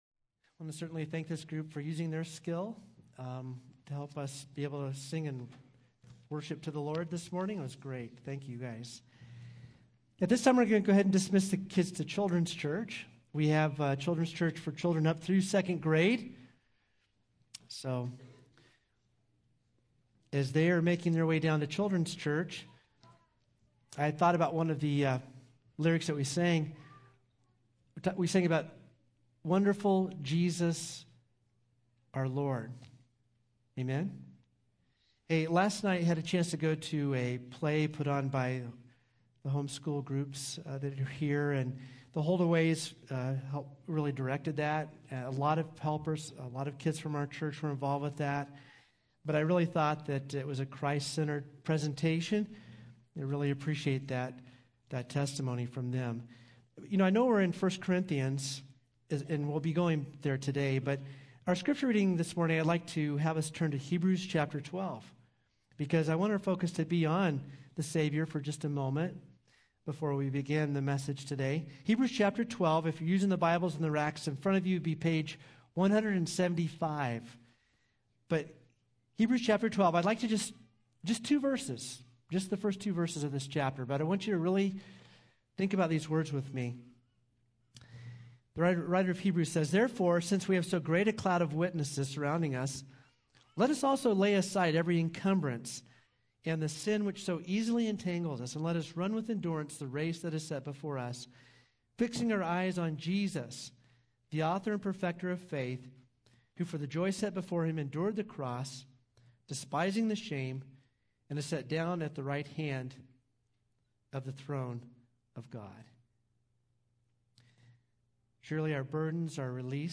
4-23-23-Sermon.mp3